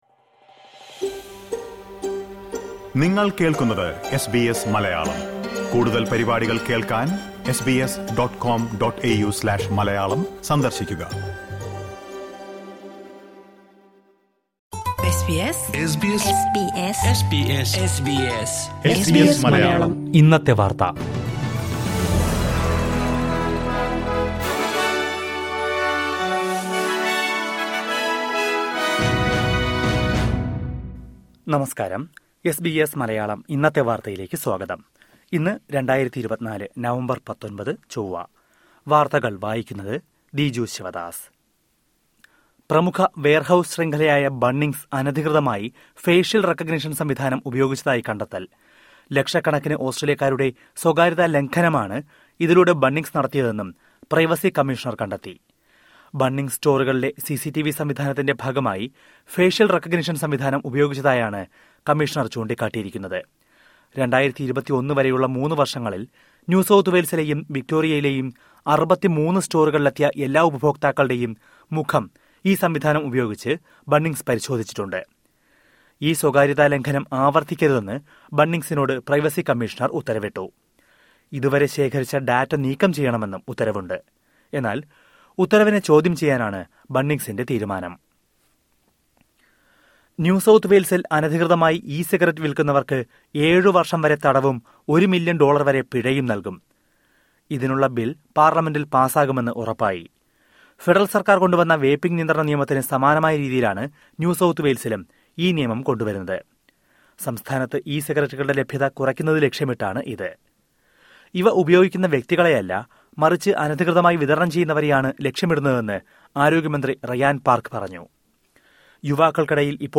2024 നവംബര്‍ 19ലെ ഓസ്‌ട്രേലിയയിലെ ഏറ്റവും പ്രധാന വാര്‍ത്തകള്‍ കേള്‍ക്കാം...